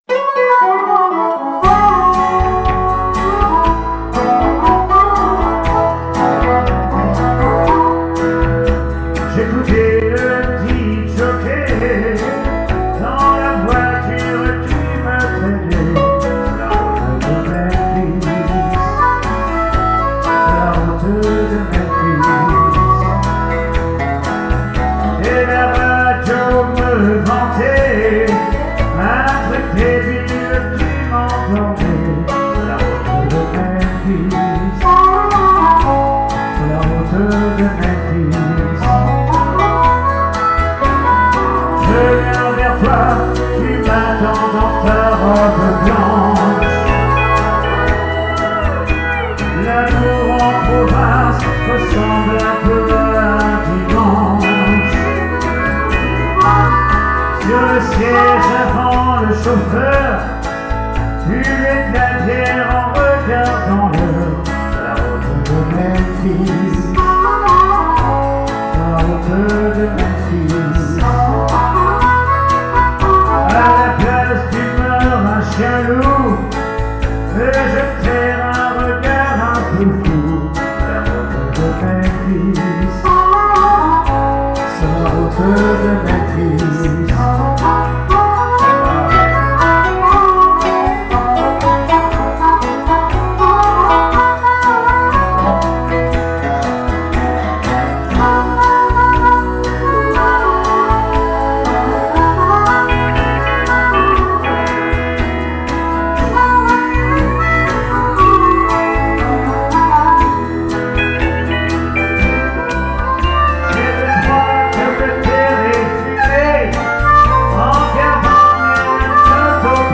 SOIREES BLUES-ROCK RETROSPECTIVE
DUO CHANT/HARMONICA
maquettes